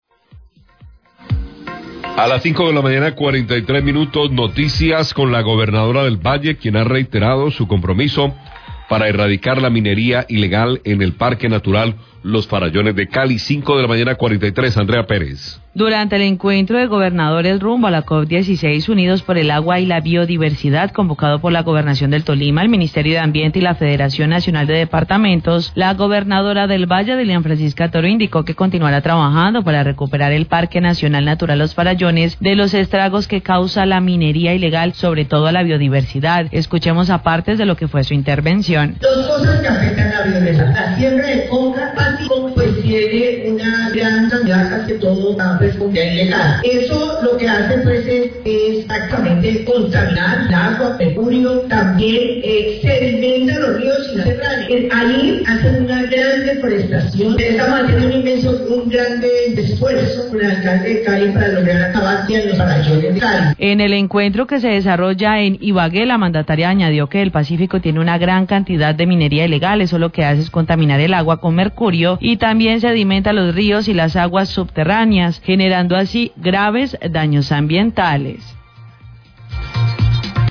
Radio
En el Encuentro nacional de Gobernadores, la Gobernadora del Valle, Dilian Francisca Toro, habló sobre el trabajo que se hace en los Farallones de Cali para terminar con la minería ilegal.